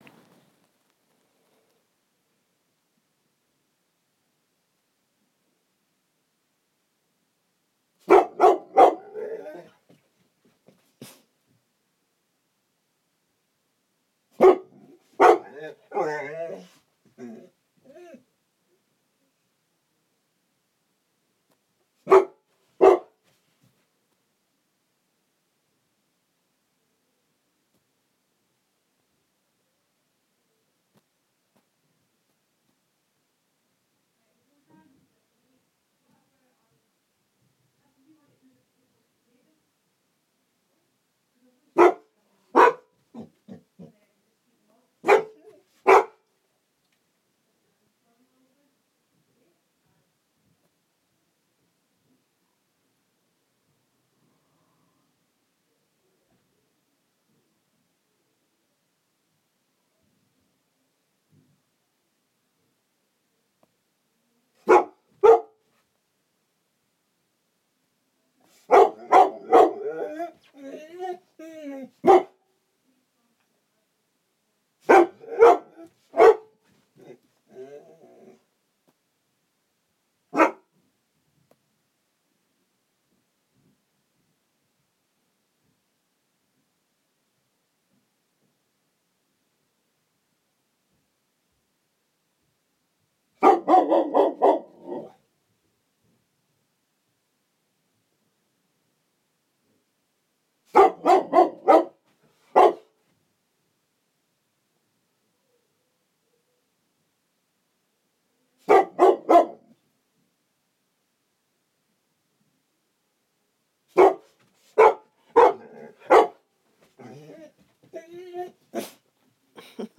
zeb barking